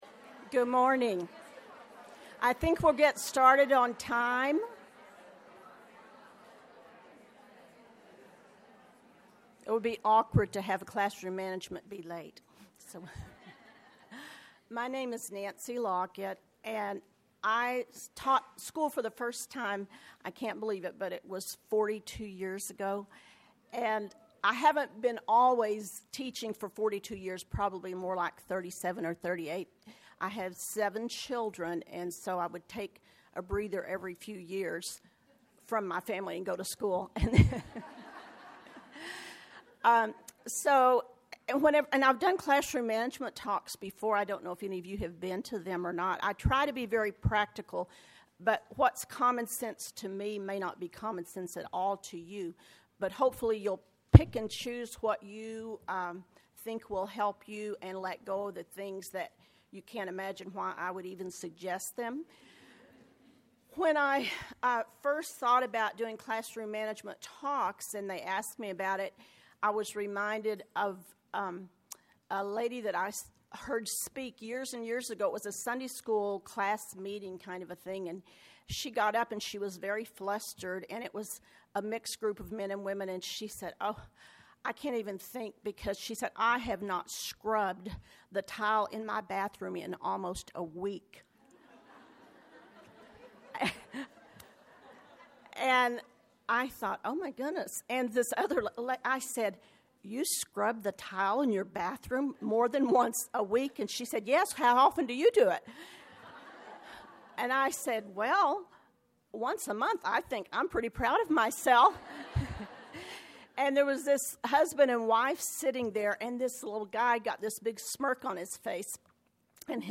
We will discuss methods for organizing your classroom and your students to bring order and beauty to both. Speaker Additional Materials The Association of Classical & Christian Schools presents Repairing the Ruins, the ACCS annual conference, copyright ACCS.